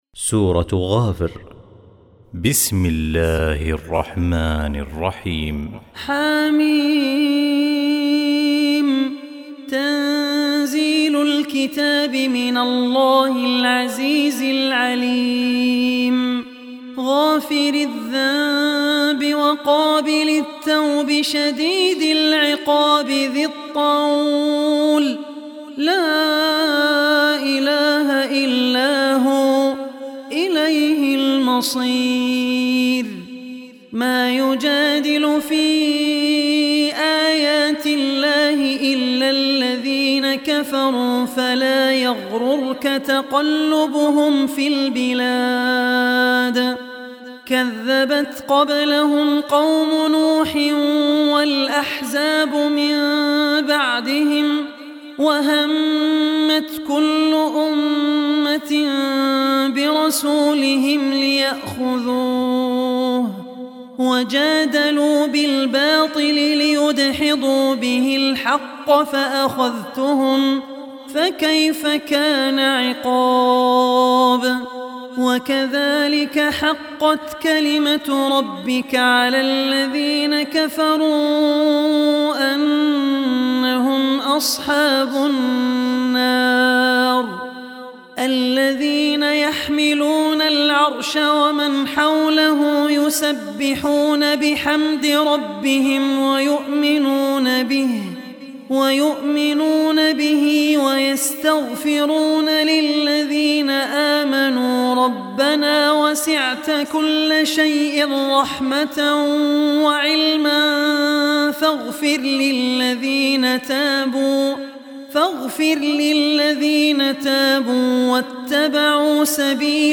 Surah Ghafir Online Recitation by Al Ossi
Surah Ghafir, listen online mp3 tilawat / recitation in the voice of Abdur Rehman Al Ossi.
40-surah-ghafir.mp3